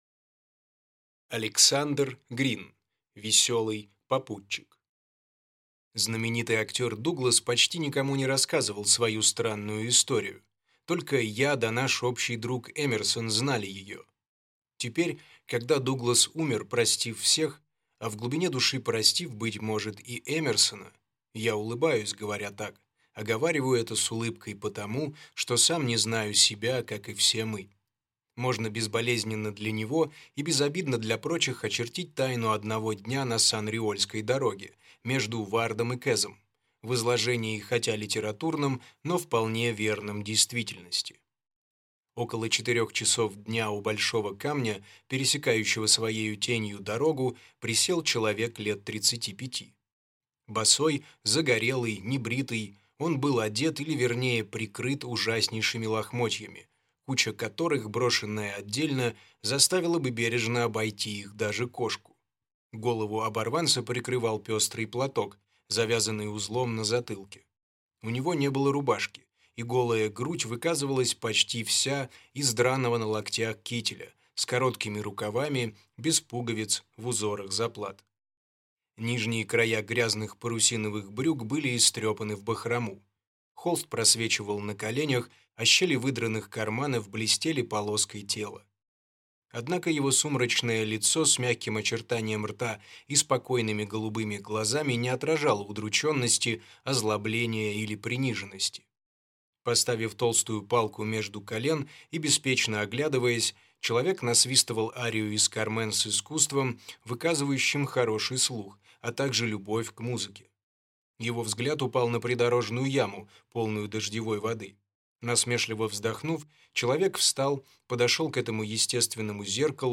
Аудиокнига Весёлый попутчик | Библиотека аудиокниг